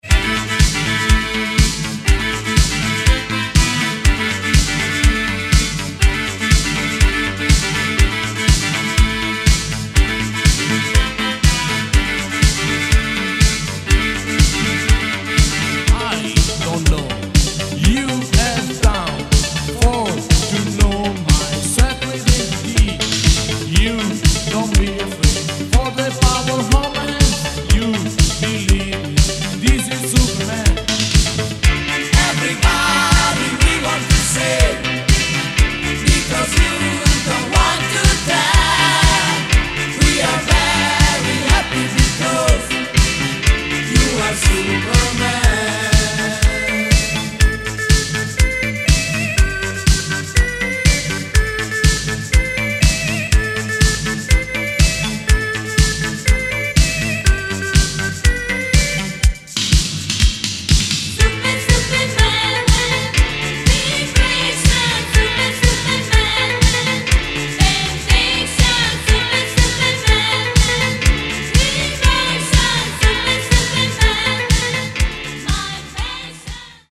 Reissue of this italo classic from 1986.